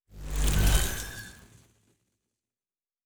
pgs/Assets/Audio/Sci-Fi Sounds/Weapons/Weapon 12 Stop (Laser).wav at master
Weapon 12 Stop (Laser).wav